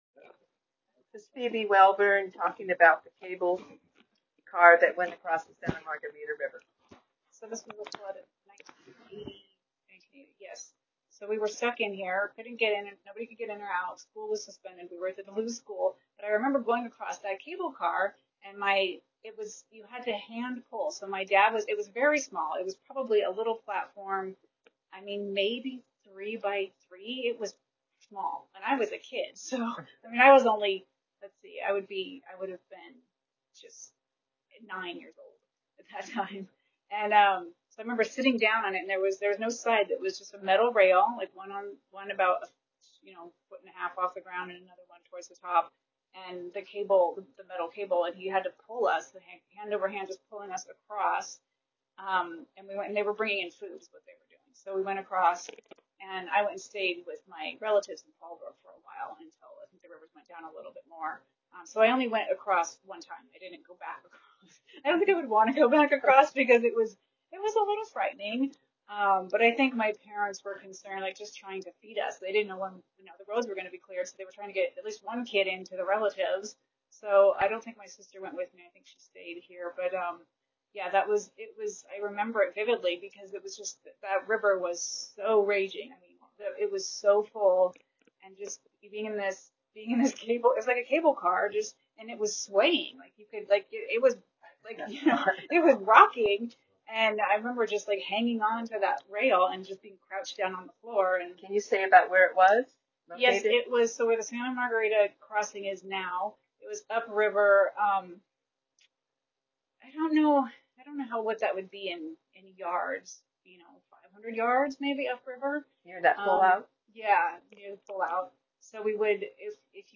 an Oral History